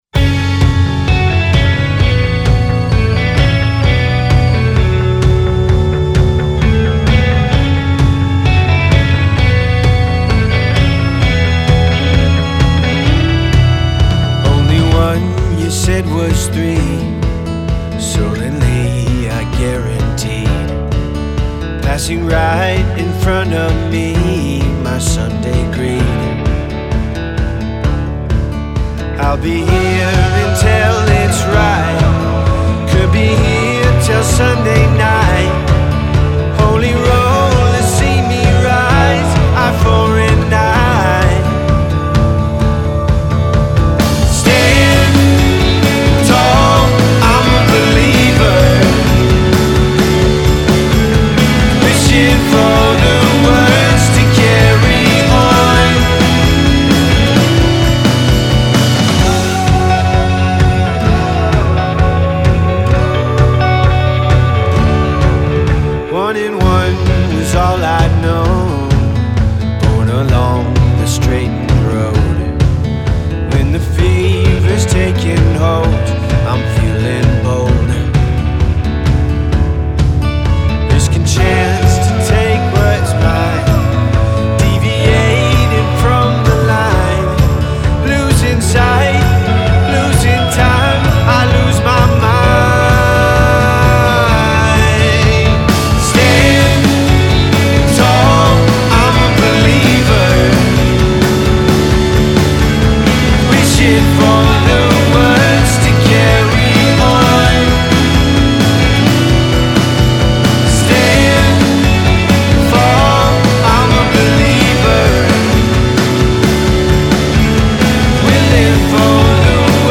more traditional pop/rock path for this album